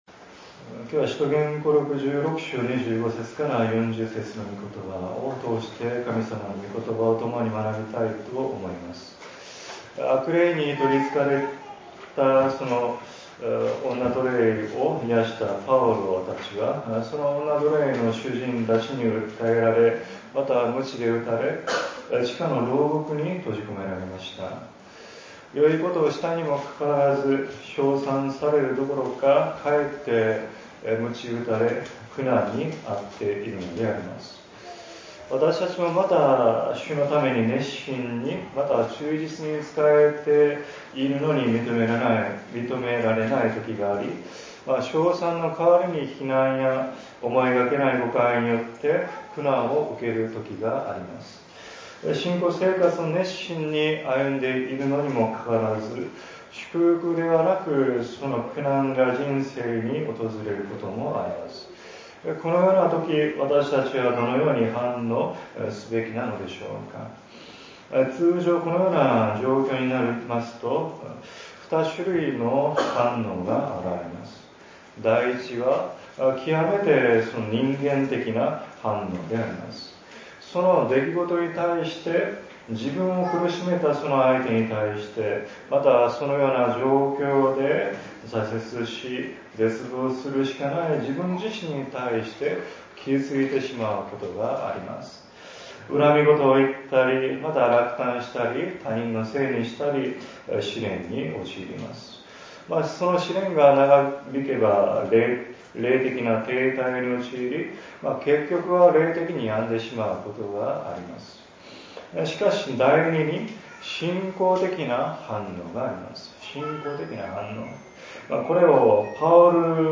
2025年-礼拝次第